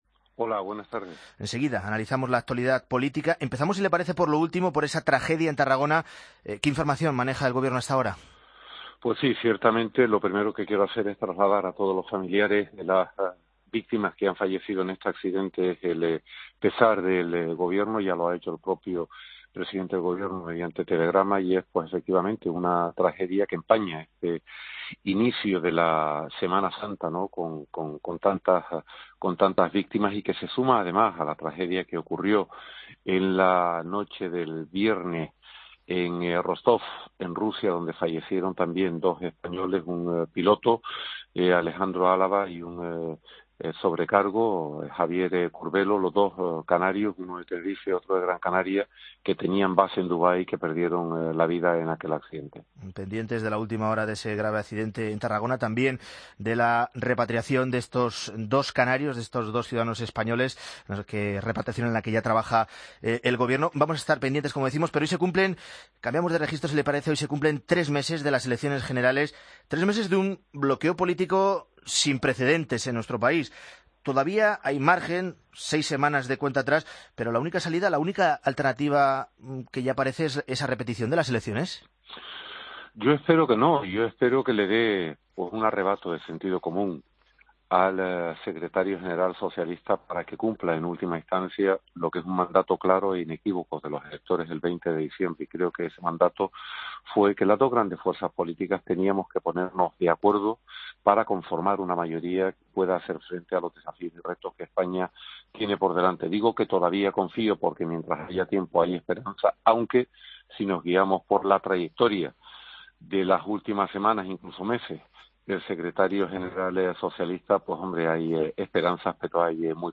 AUDIO: Escucha la entrevista a José Manuel Soria, Ministro en funciones de Industria, Turismo y Comercio, en Mediodía COPE